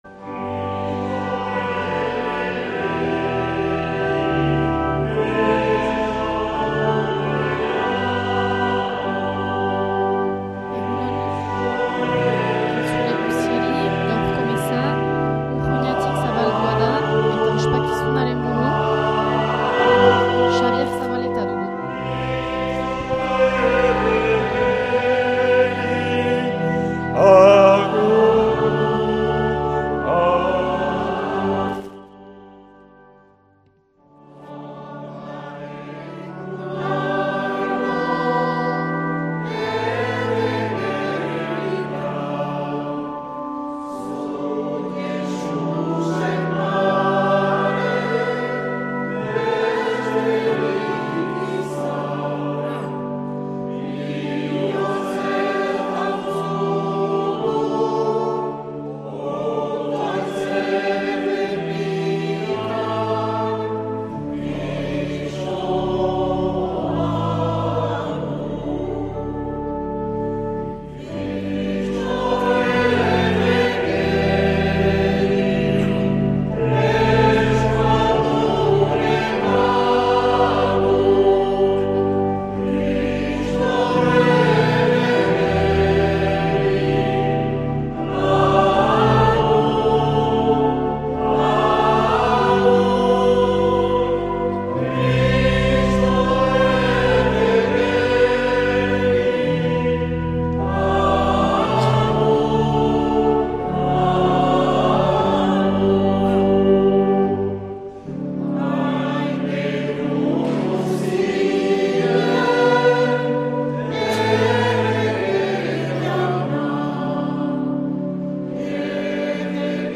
Igandetako Mezak Euskal irratietan